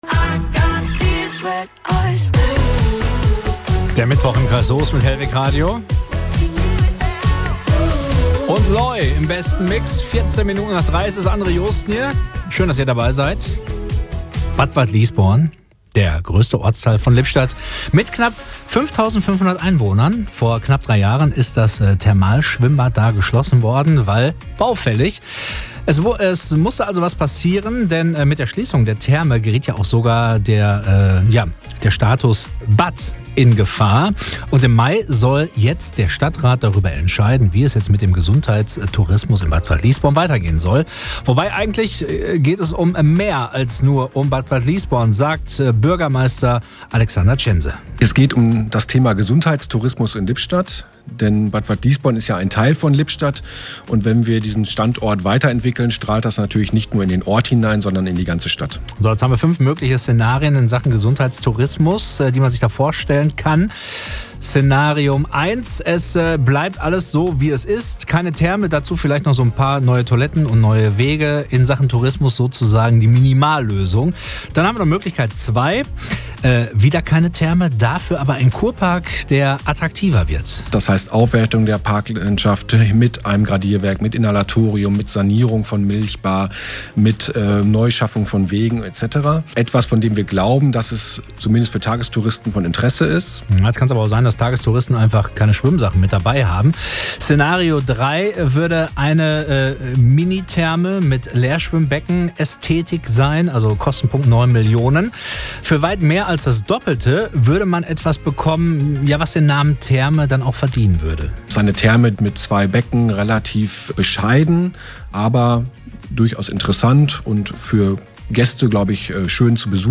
Bürgermeister Alexander Tschense macht im Gespräch mit Hellweg Radio deutlich, dass die Entwicklung weitreichende Folgen hat: